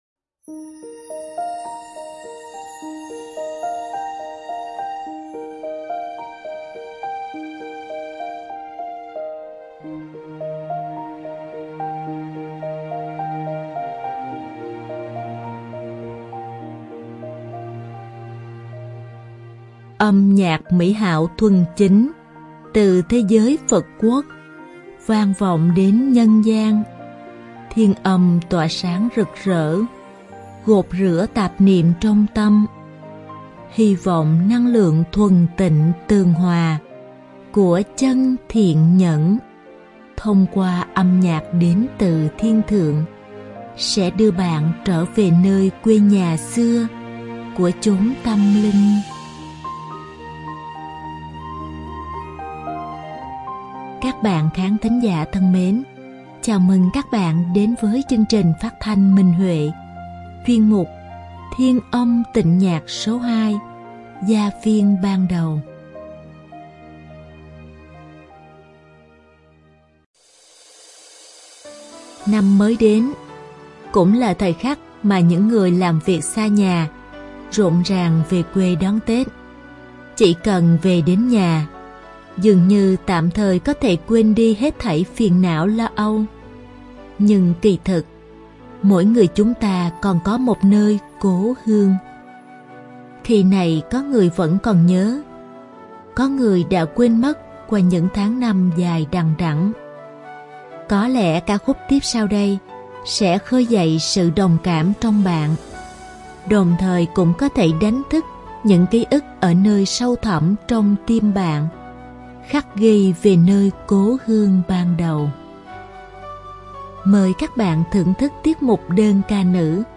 Đơn ca nữ
Đơn ca nam
Nhạc khúc